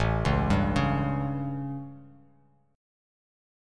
A7 Chord
Listen to A7 strummed